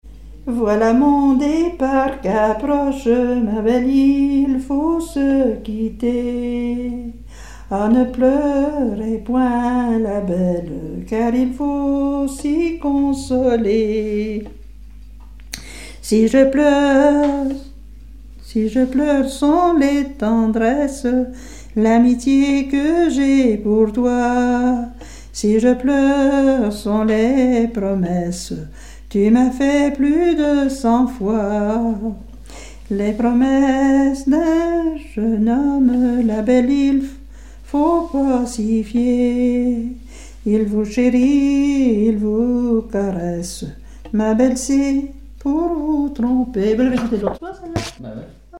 Genre dialogue
Pièce musicale inédite